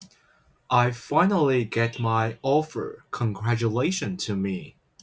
I_am_happy_get_my_offer.wav